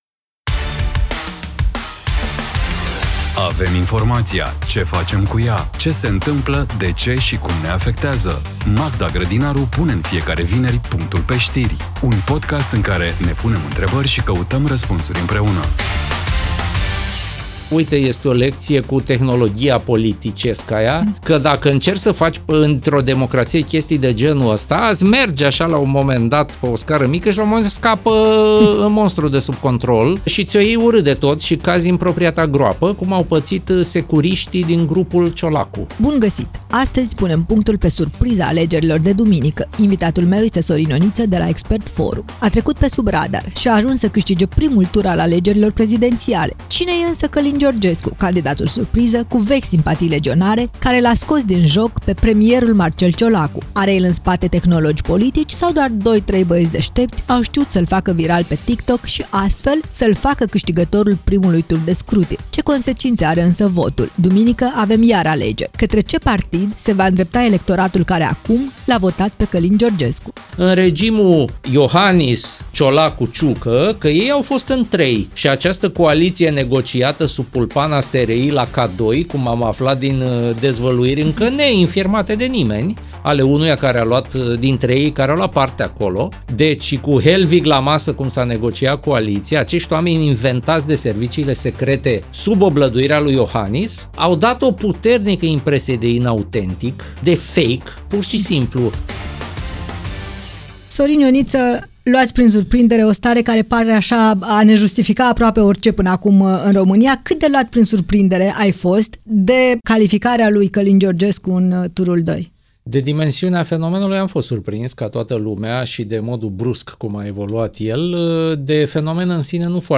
Un dialog inteligent, relaxat și necesar.